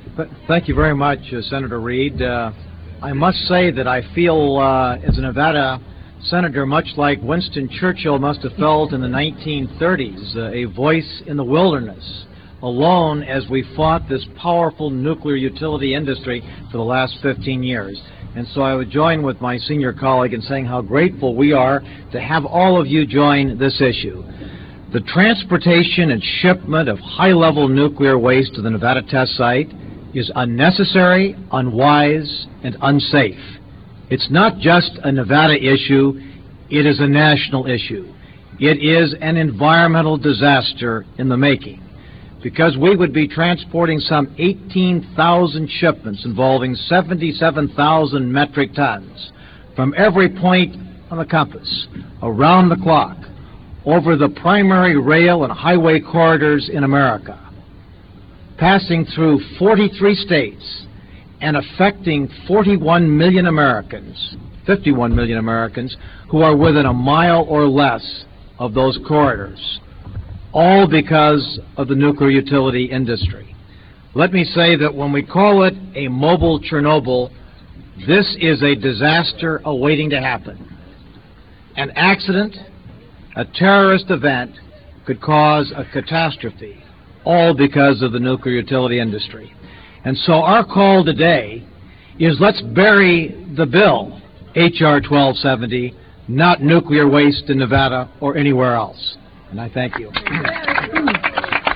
lifeblood: bootlegs: 1997-09-24: honor the earth press conference - washington, d.c.
05. press conference - senator richard bryan (1:41)